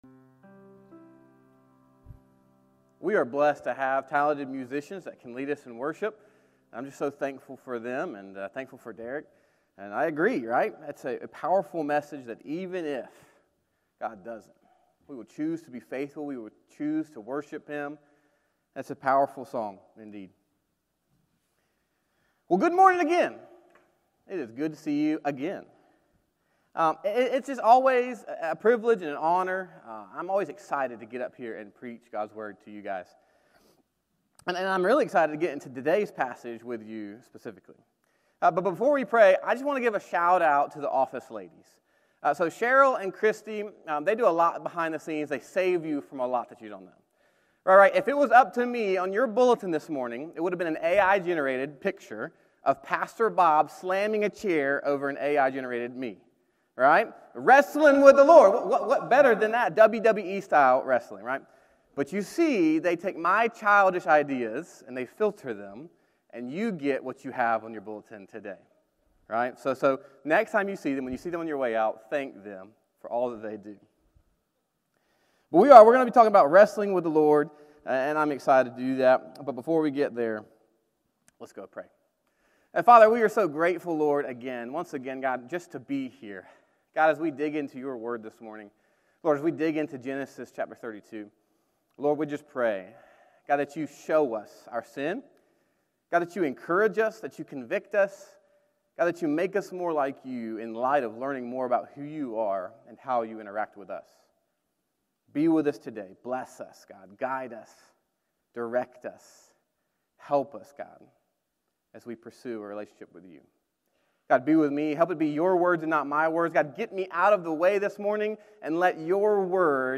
Morning Worship - 11am